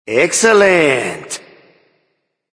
baronsamedi_vox_vgs_emote_a.mp3